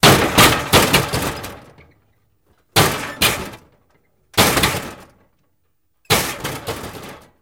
Coke Machine Hits Biggest x4